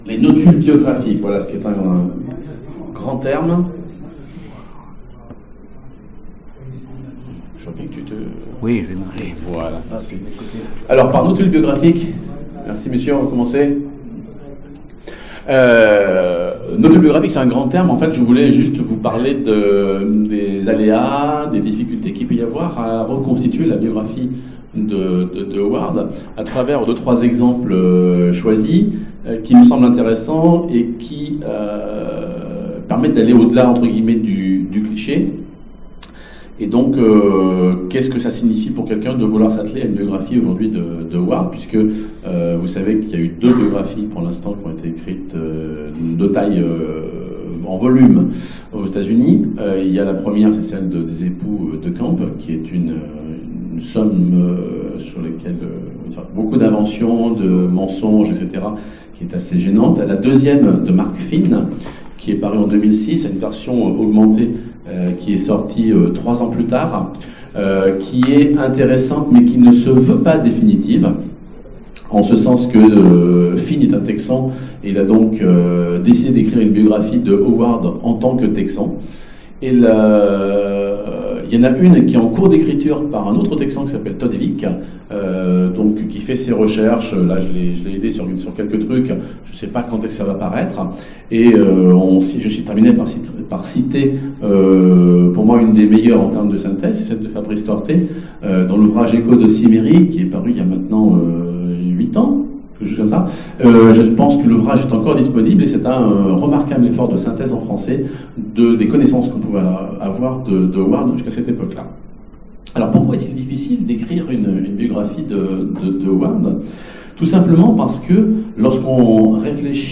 Conférence FMI 2017 : Robert E. Howard, notules biographiques